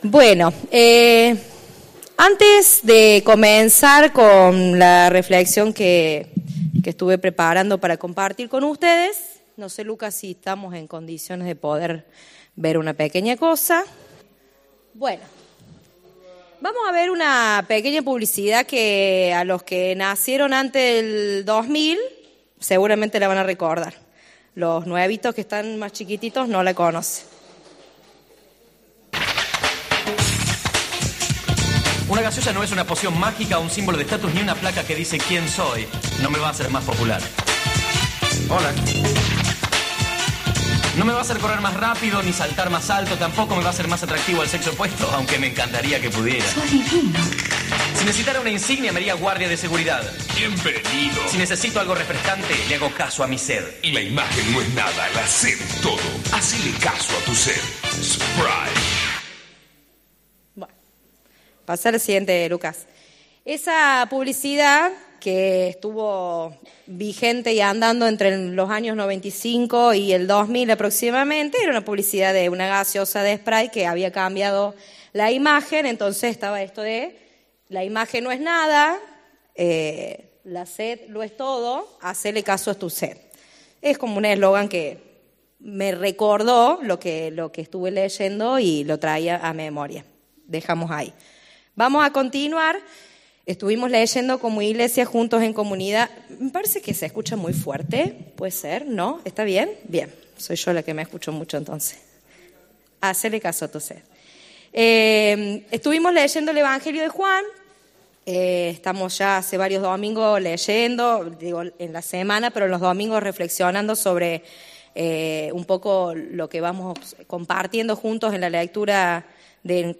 Aquí presentamos los mensajes dominicales de nuestra iglesia, además de otras grabaciones y videos que consideramos de interés, como los realizadas en encuentros, charlas, talleres, etc.